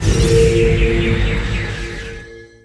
SFX event_roombonus.wav